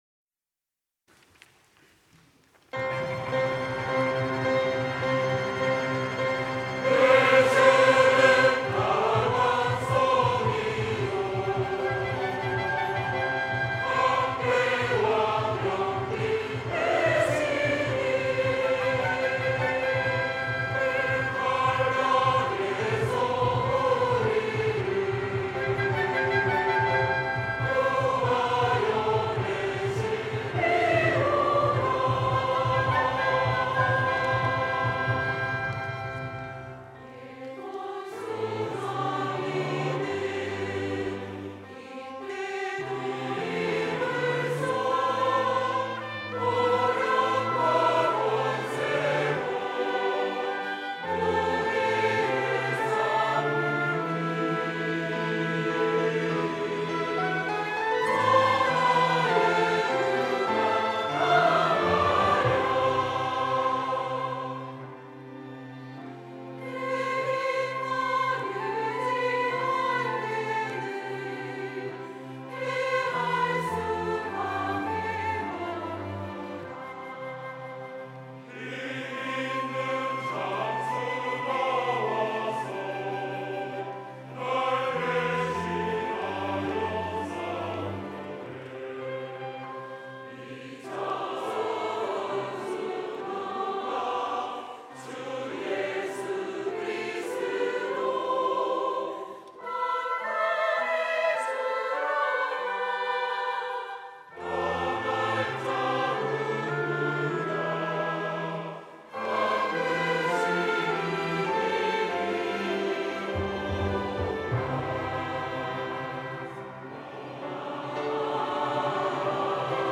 특송과 특주 - 내 주는 강한 성이요
연합 찬양대